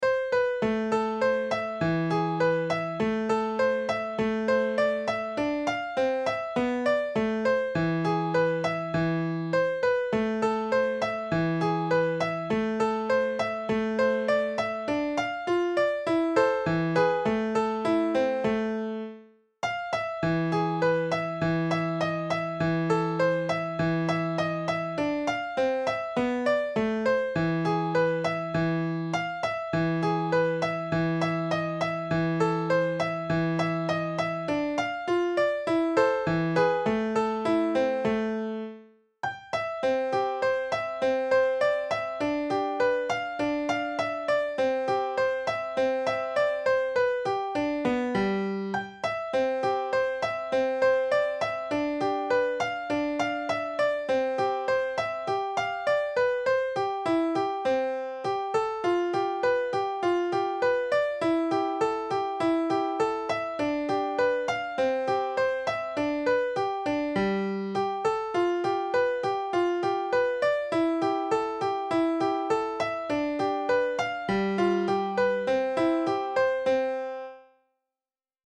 Lilypond will also let you export to midi, here’s what the song sounds like when a computer plays it: in MIDI, and
etude-mid.mp3